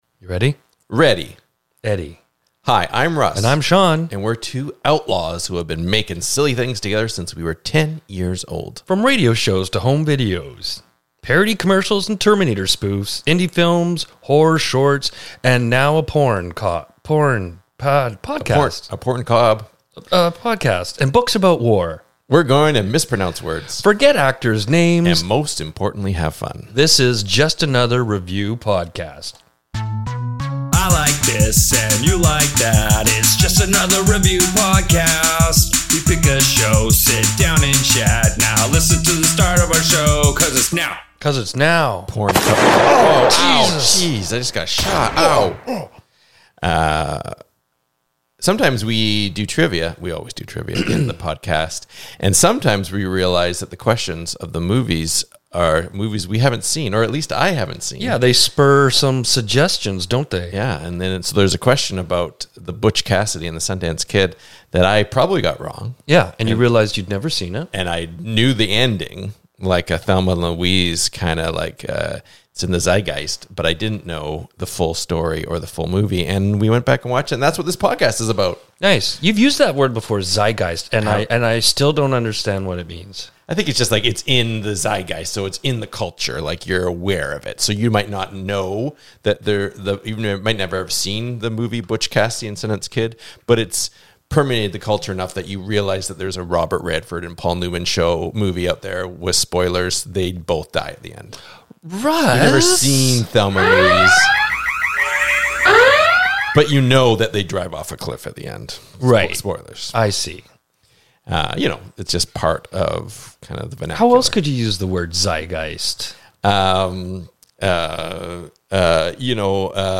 The two Outlaws mispronounce words, forget actors names, and most importantly have fun. This is Just Another Review Podcast (or JAPR for short).